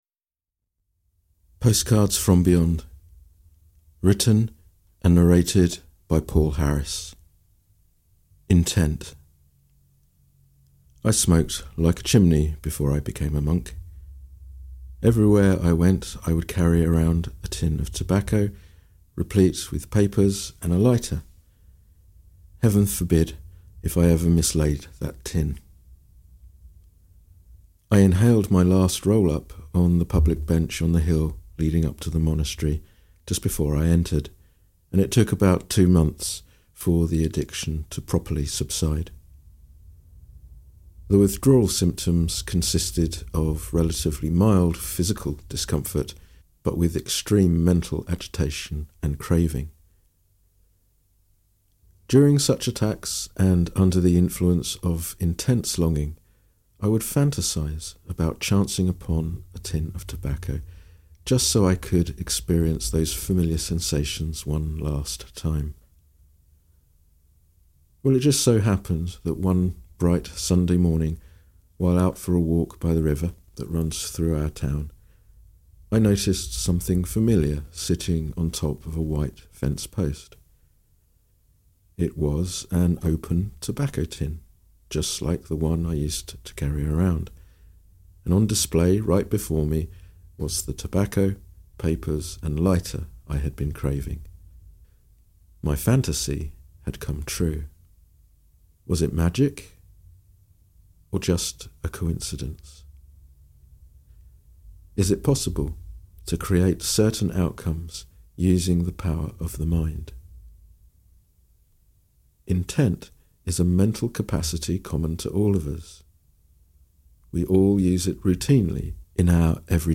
Audio recording of the book